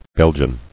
[Bel·gian]